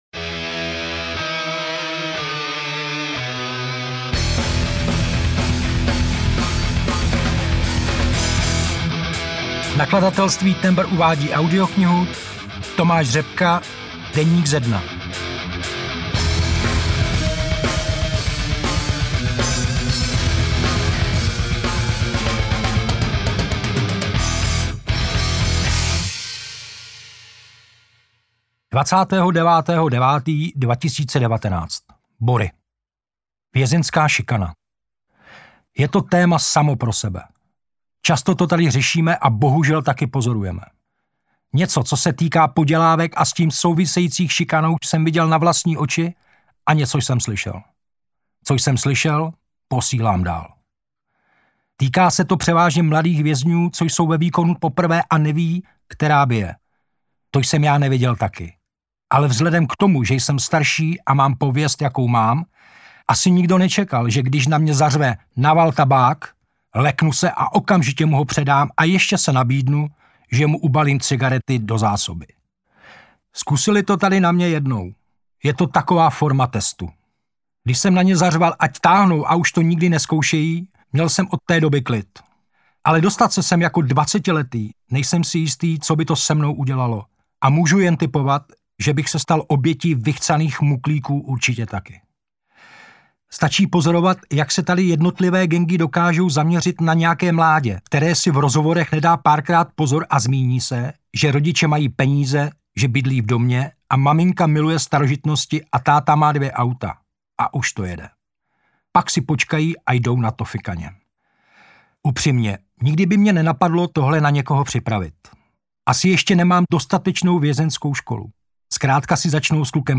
Audiobook
Read: Tomáš Řepka